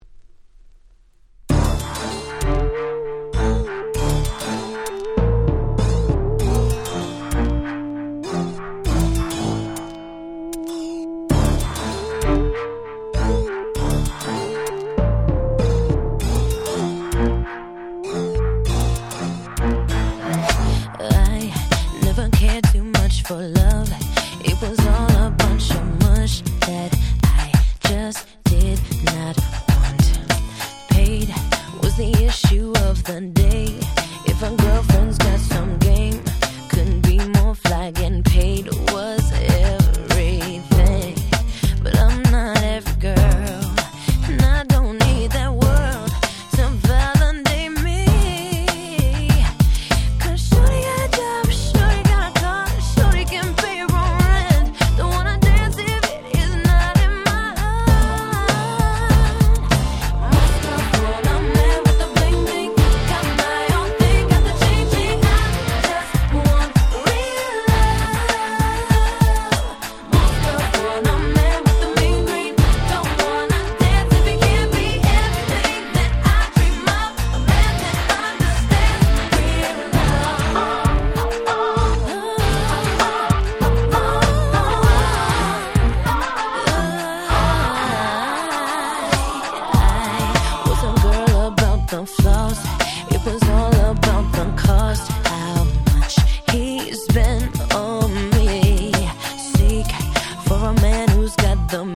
00' Super Hit R&B / Pops !!